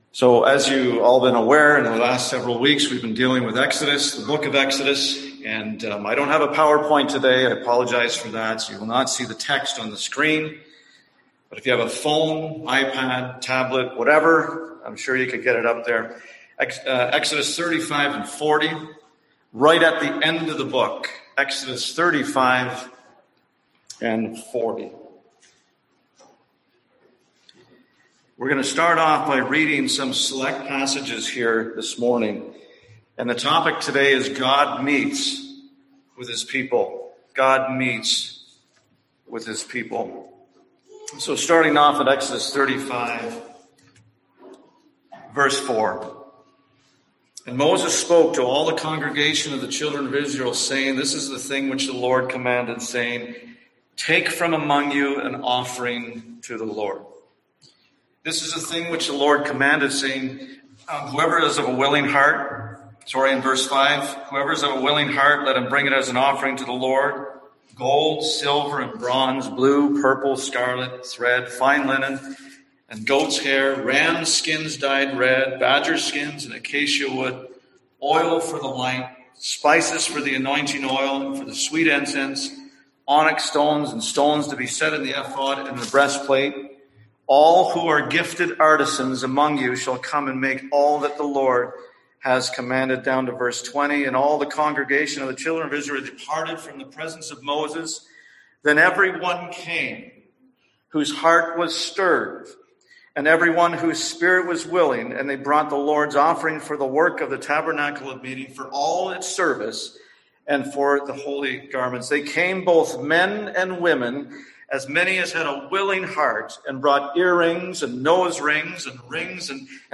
Passage: Exodus 35, Exodus 40 Service Type: Sunday AM